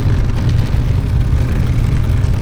Converted sound effects